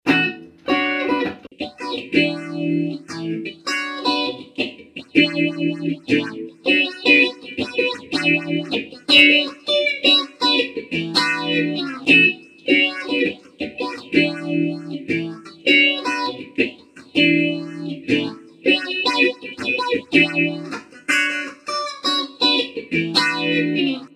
Guitar effect processor (2000)
demo guitar modulation